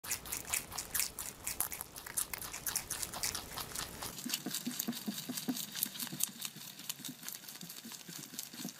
Звук кролика который кушает